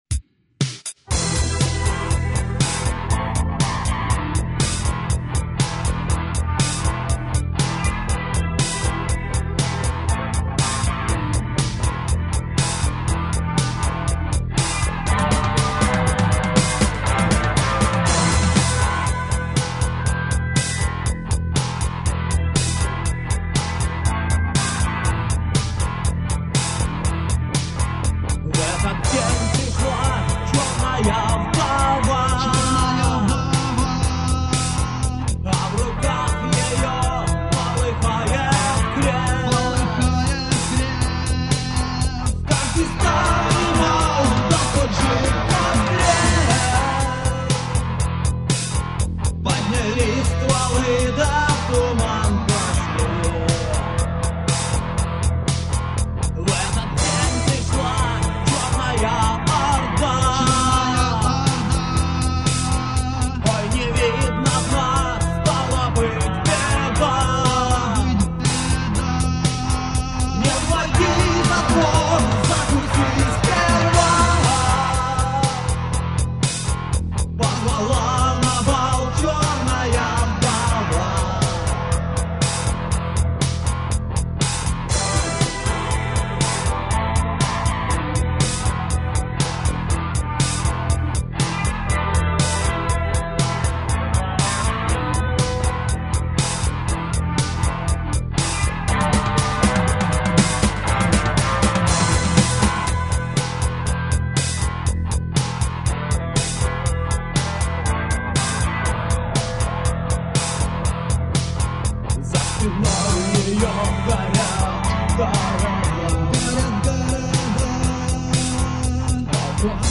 *Рок - Музыка